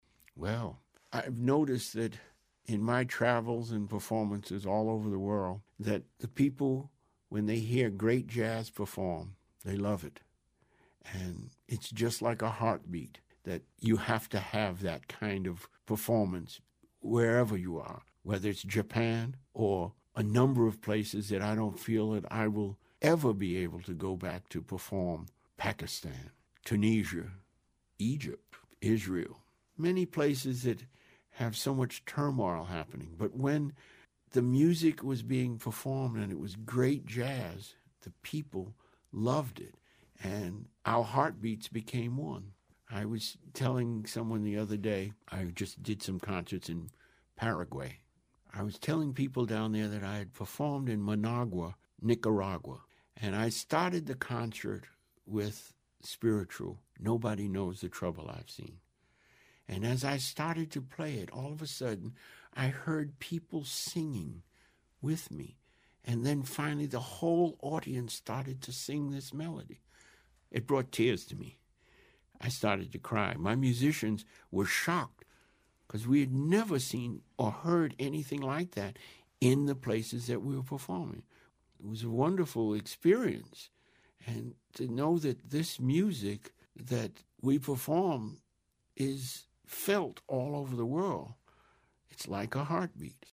In this excerpt from the podcast, Owens explains why he calls jazz ”the heartbeat of the world.” [1:33]